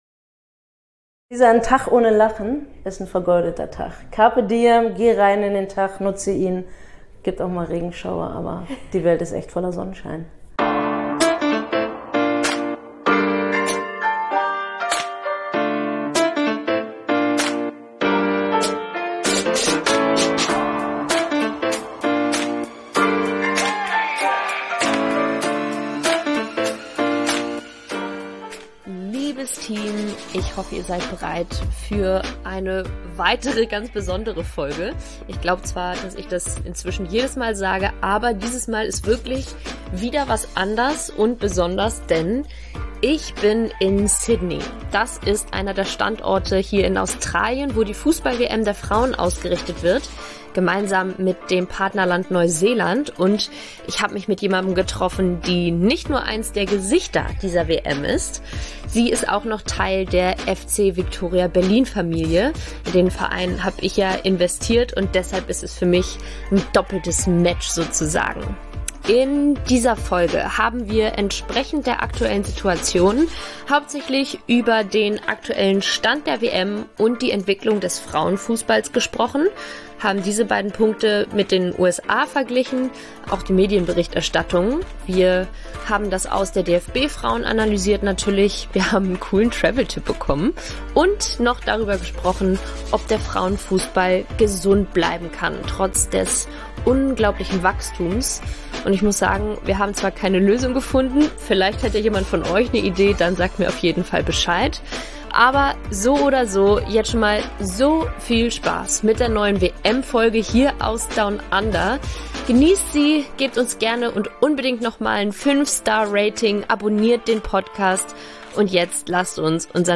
Mit meiner "Kollegin" vom FC Viktoria Berlin, der zweimaligen Weltmeisterin, viermaligen Europameisterin und dreimalige Olympia-Bronzegewinnerin spreche ich über everything world cup. Dazu gehört natürlich auch eine ausführliche Analyse des DFB-Ausscheidens, wir vergleichen die deutsche und amerikanische Berichterstattung (Ari ist als Expertin für Fox News in Sydney) und bekommen einen Einblick in Aris Mindset über das Leben.